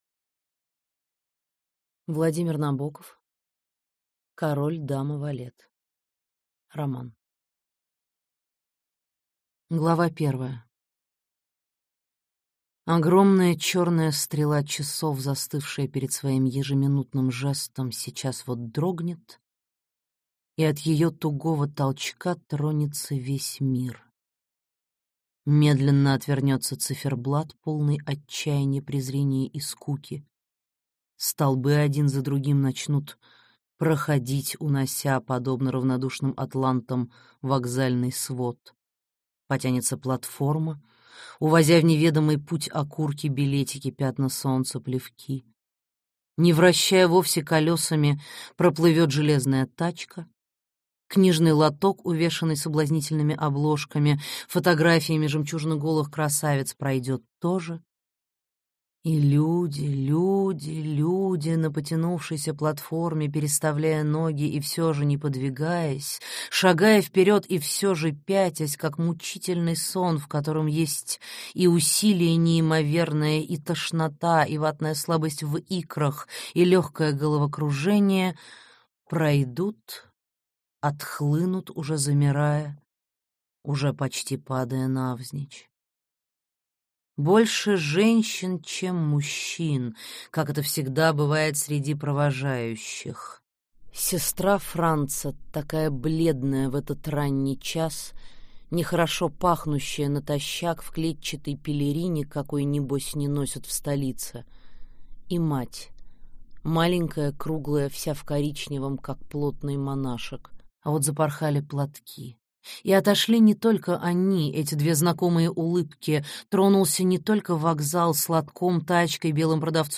Аудиокнига Король, дама, валет | Библиотека аудиокниг